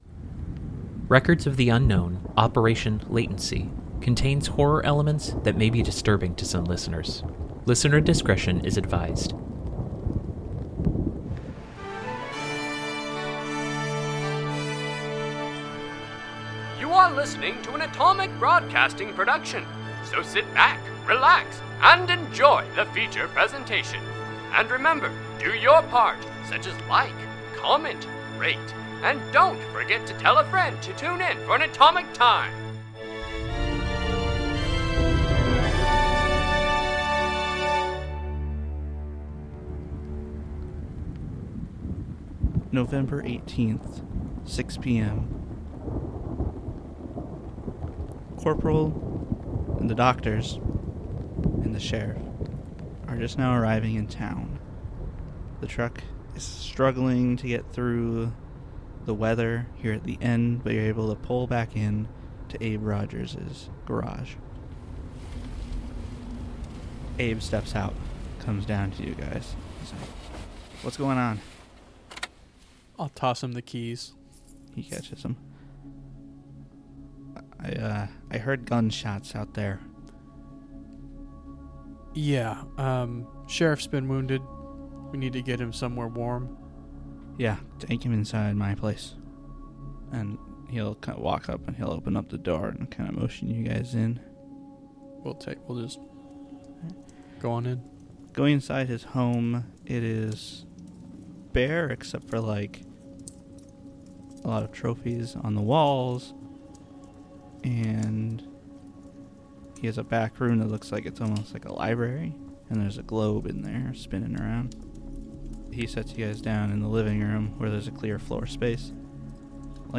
Records of the Unknown is an unscripted improvisational podcast based on the game Delta Green b